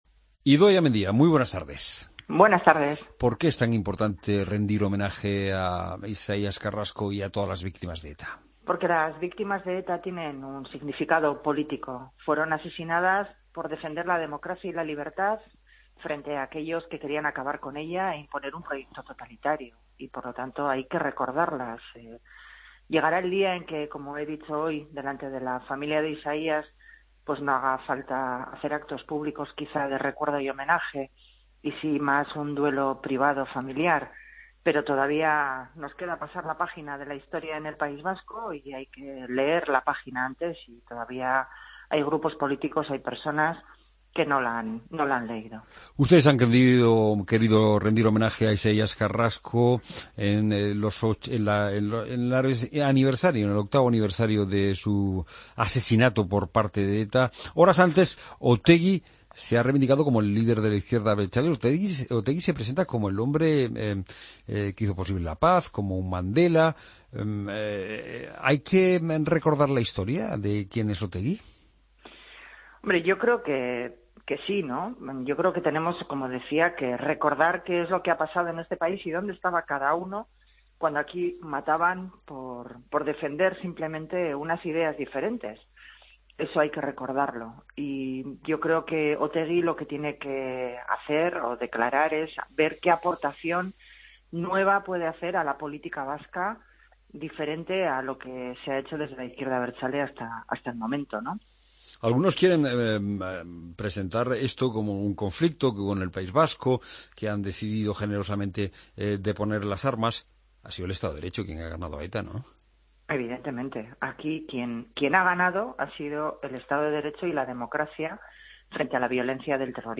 AUDIO: Escucha la entrevista a Idoia Mendia, secretaria general del PSE-EE, en Mediodía COPE